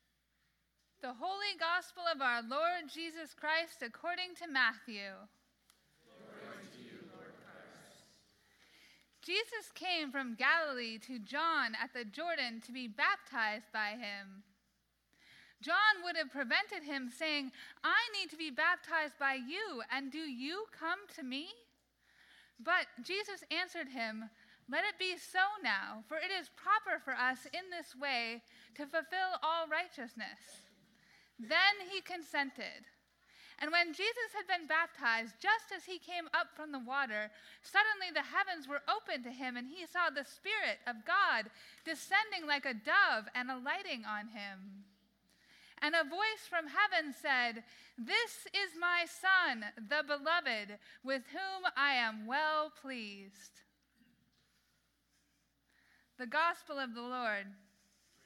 Gospel Reading: Matthew 3:13-17